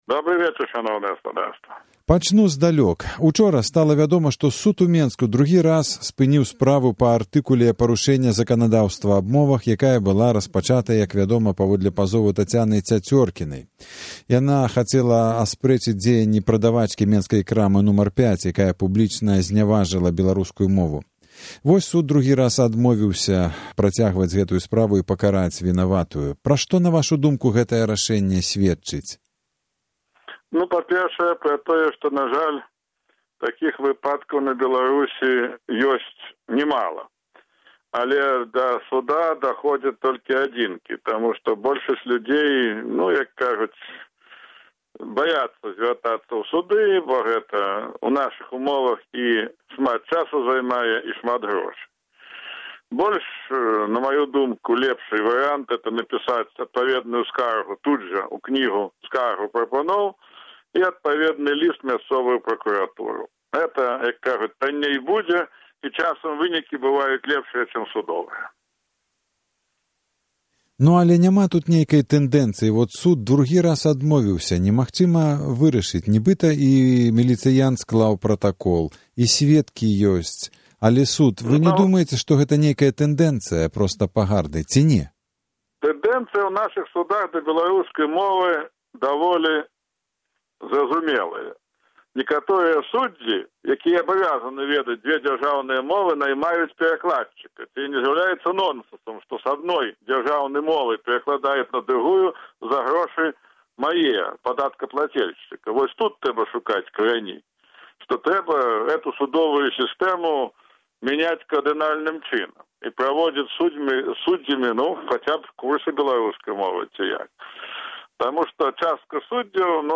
Інтэрвію з Алегам Трусавым